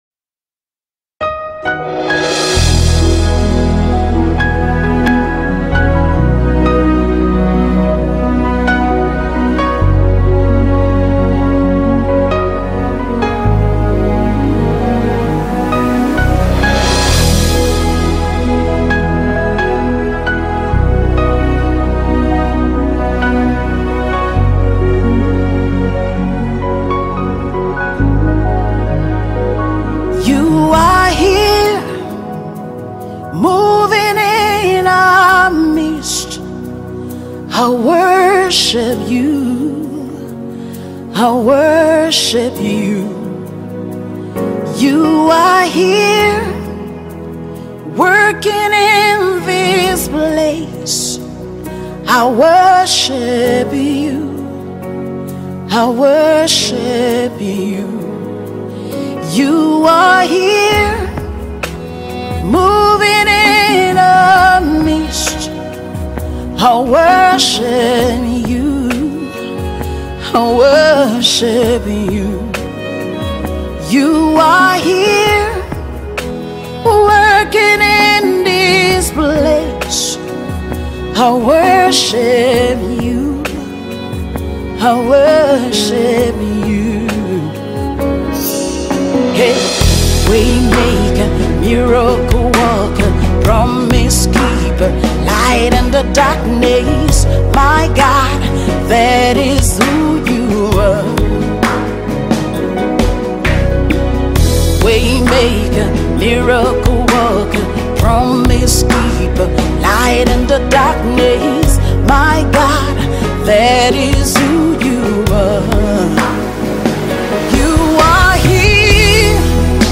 Gospel Songs Nigerian Gospel Songs